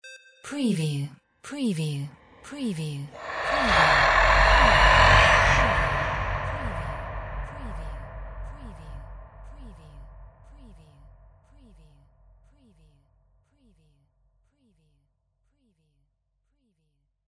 Dragon Growl Sweetener 03
Stereo sound effect - Wav.16 bit/44.1 KHz and Mp3 128 Kbps
previewSCIFI_DRAGON_SWEETNERS_WBHD03.mp3